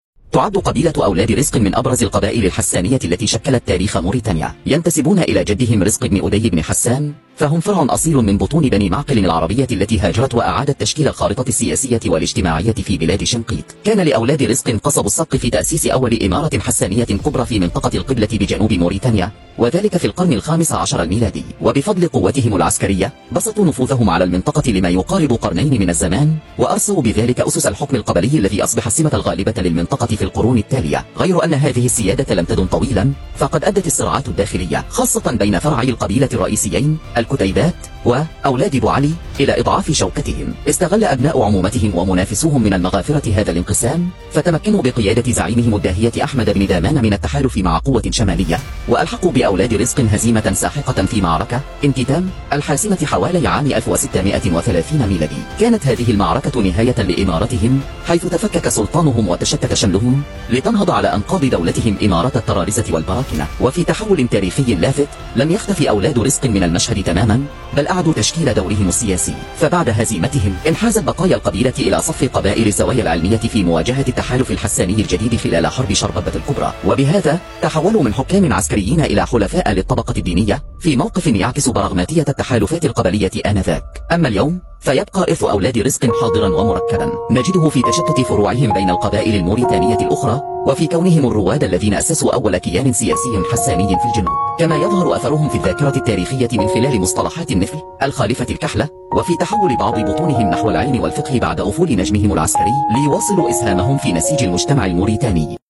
Pour en savoir plus, écoutez ce récit en arabe sur l’histoire d’une tribu fondatrice du sud mauritanien :